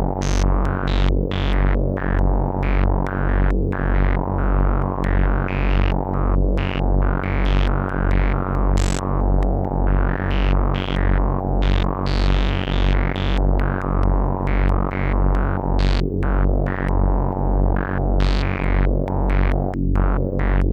When I turn the slew all the way down, there's a substantial click with every clock pulse.
Here's an example of the click:
That audio file is a pair of MOTM 300 oscillators (one FM modulated by the Random out of the board) into a MOTM 490 Moog filter. The filter is FM modulated by the S&H. I'm using a MOTM 190 VCA as an attenuator only.
yu_sh_click.wav